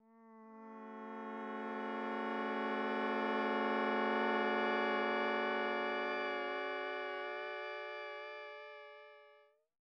Voice_process.wav